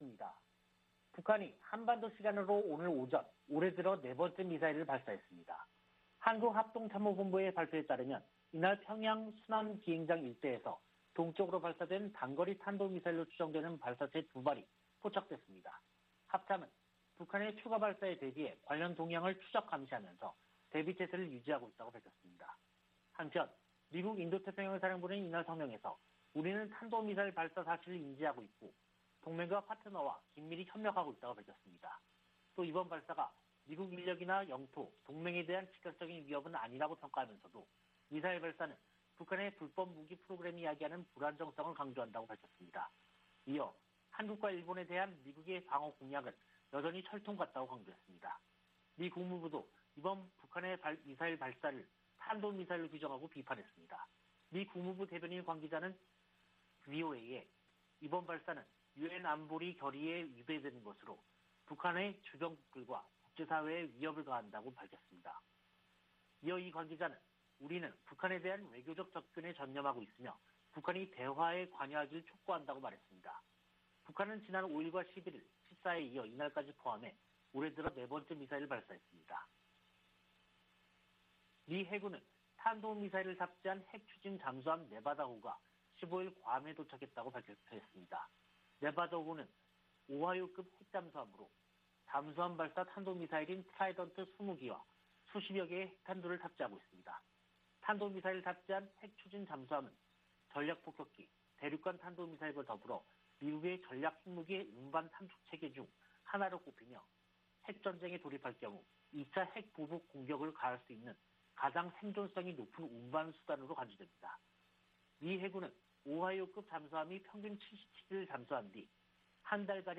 VOA 한국어 간판 뉴스 프로그램 '뉴스 투데이', 2022년 1월 17일 3부 방송입니다. 북한이 17 일 또 다시 단거리 탄도미사일 2발을 발사했습니다.